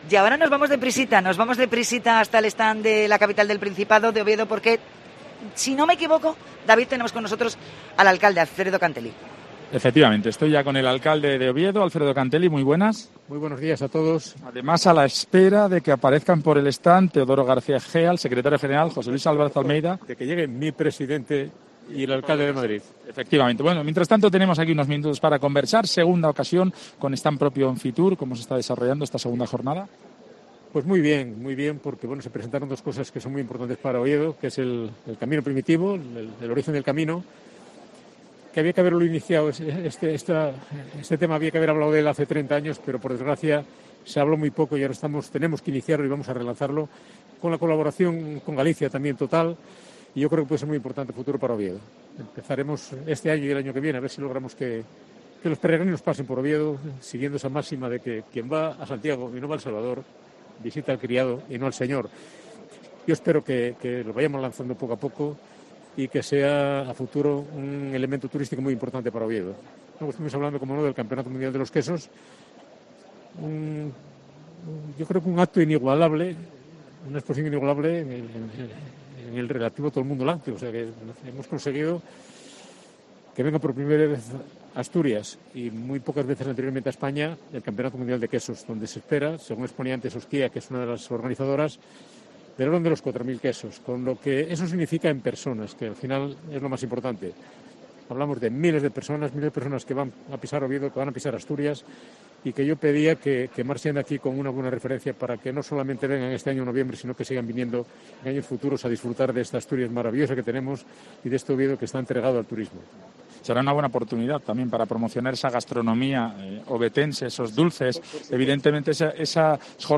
Entrevista al alcalde de Oviedo, Alfredo Canteli